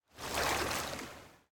artifact_water.0.ogg